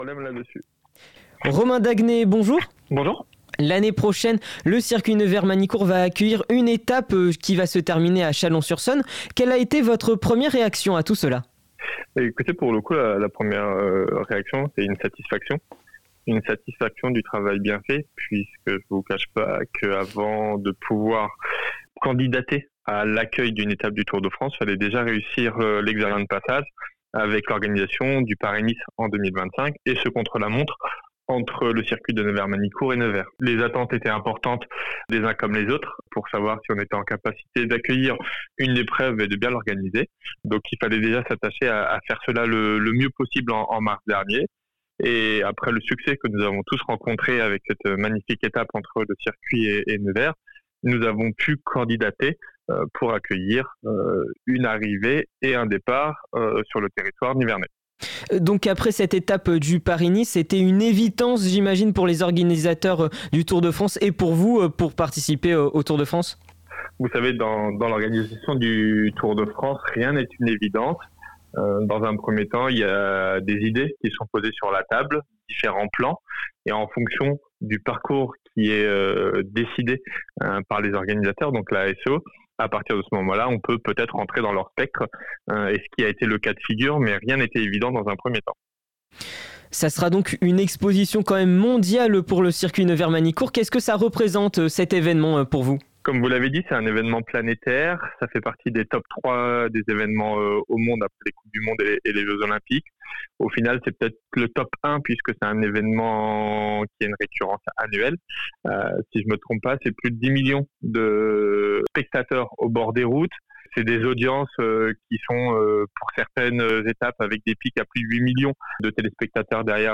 Ils sont élus, maires, sénateurs, députés ou tout simplement citoyen investit dans leurs communes… Rencontre avec ceux qui font l’actualité du territoire.